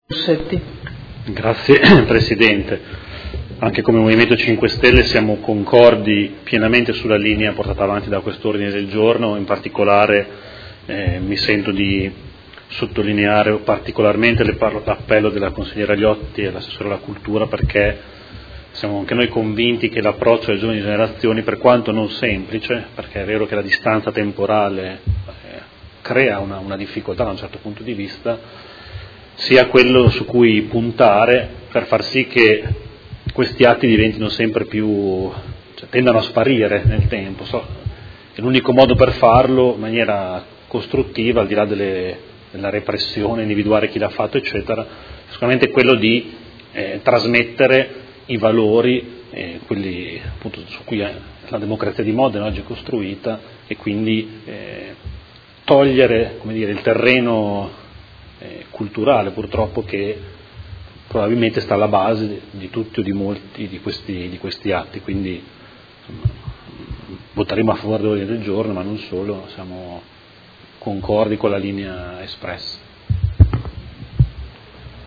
Mario Bussetti — Sito Audio Consiglio Comunale